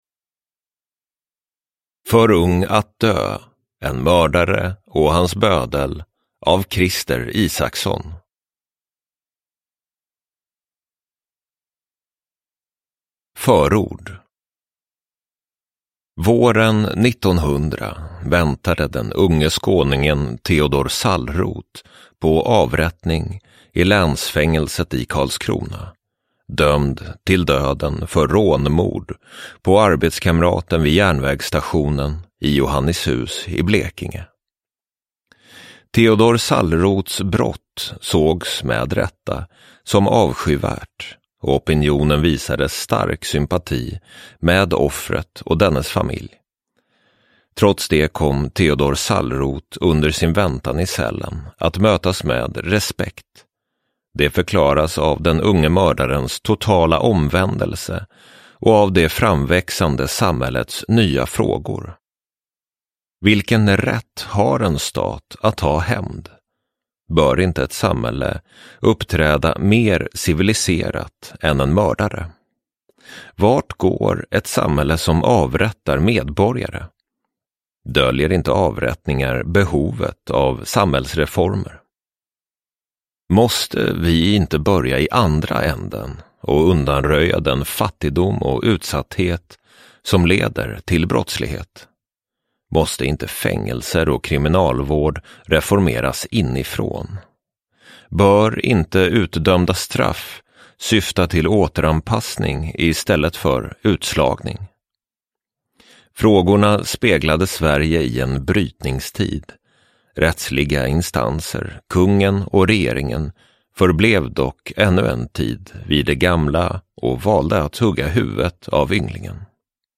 För ung att dö : en mördare och hans bödel - om en av de sista avrättningarna i Sverige – Ljudbok – Laddas ner